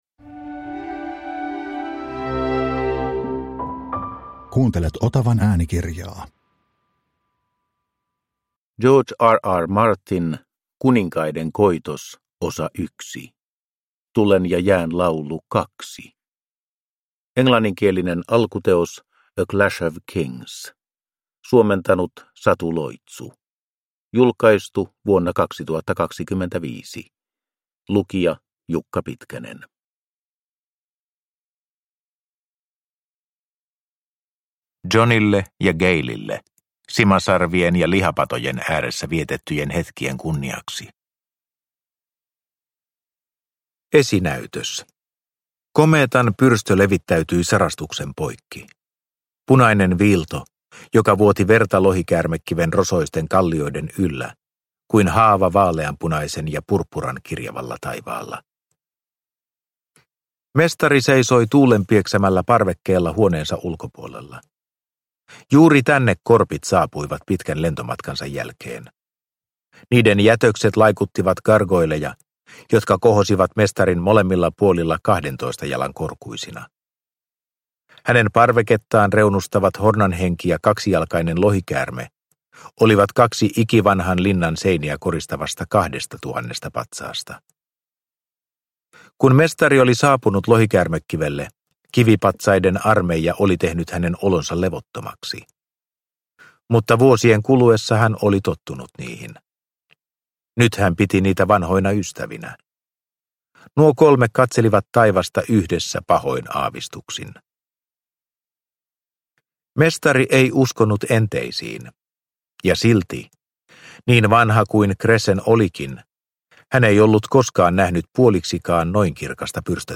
Kuninkaiden koitos 1 – Ljudbok
Maailman suurin tv-tuotanto Game of Thrones perustuu Tulen ja jään laulu -fantasiasaagaan. Äänikirjan ensimmäinen osa.